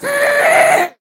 sounds / mob / ghast / scream1.ogg
scream1.ogg